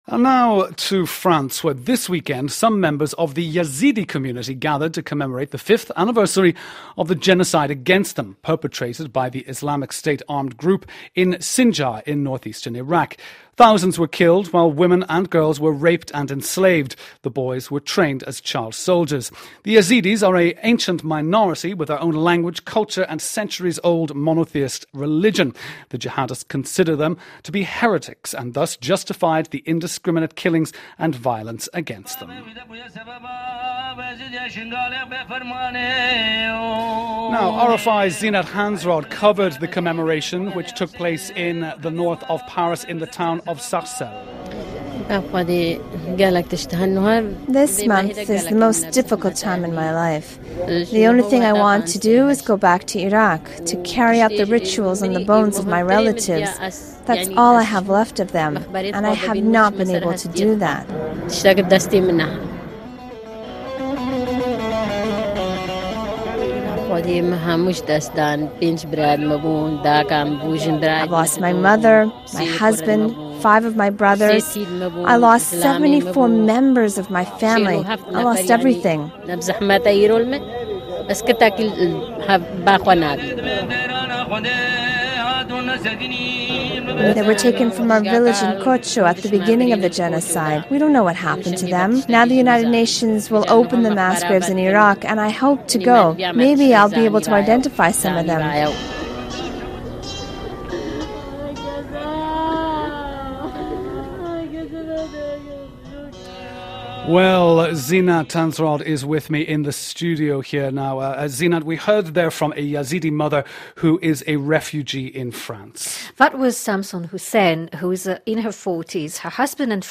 Yazidis gathered in the north of Paris to commemorate the genocide which began on 3 August 2014. The survivors and their children are refugees in France.
Yazidi_genocide_commemoration_in_Sarcelles_France.mp3